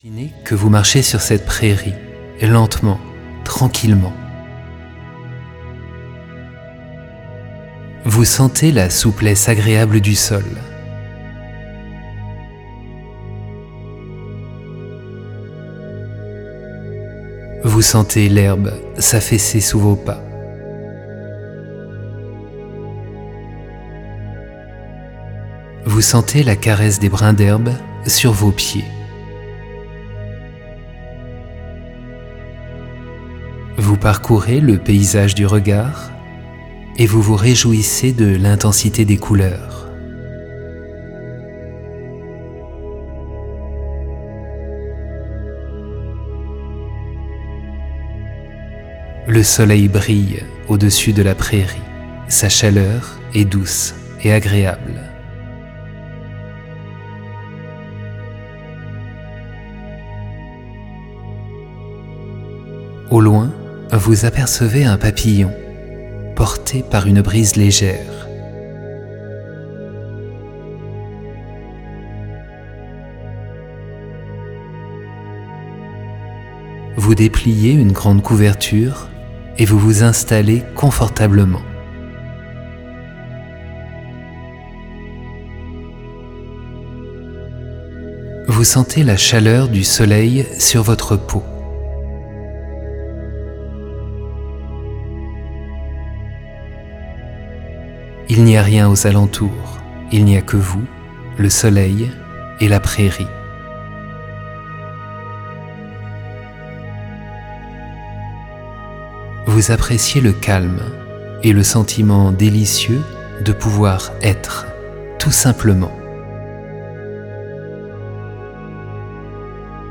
2019-04-30 Ce livre audio haut de gamme vous permettra de vous détendre facilement, de manière quasi ludique, indépendamment de toute référence religieuse ou spirituelle. Les voyages intérieurs comptent parmi les outils psychologiques les plus efficaces.